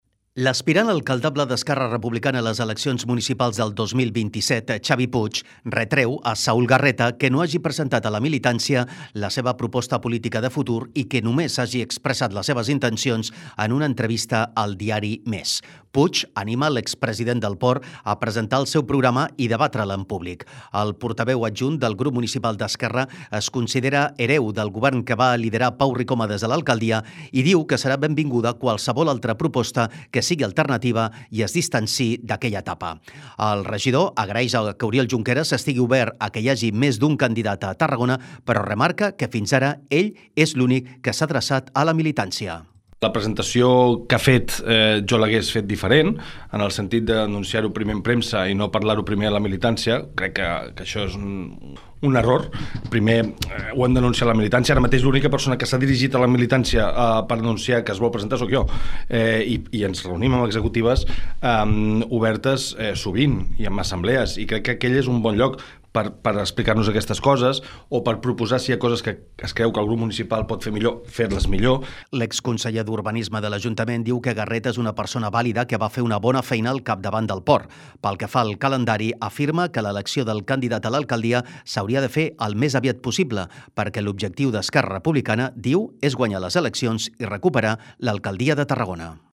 Xavi Puig, portaveu adjunt d'ERC a l'Ajuntament de Tarragona.
En una entrevista al programa ‘Bon dia Tarragona’, Puig anima l’expresident del Port a presentar el seu programa i debatre’l en públic.